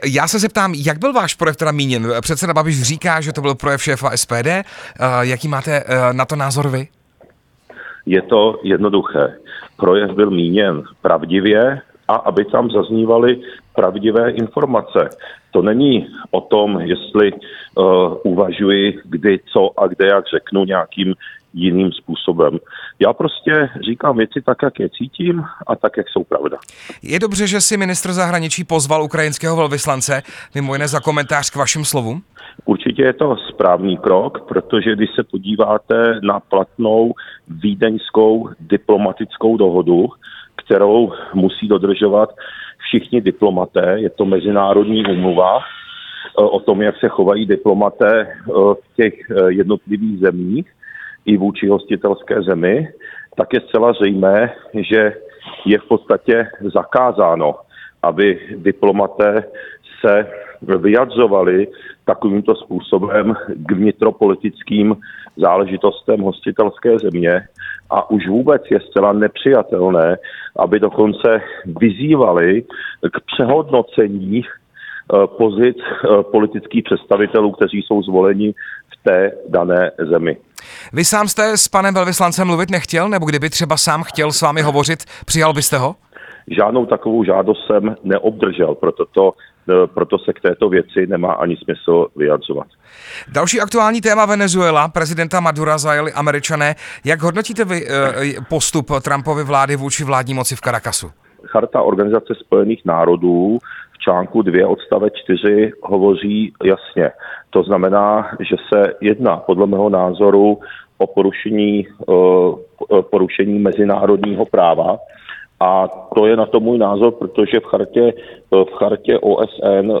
Rozhovor s šéfem sněmovny Tomiem Okamurou